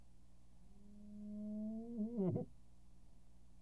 Ses pulsations sont si rapides que l’oreille humaine ne les perçoit plus comme distinctes : elles se fondent en un son continu. Ces sons sont générés par des muscles qui se contractent et se relâchent à une fréquence de 220 Hertz, soit 220 mouvements par seconde.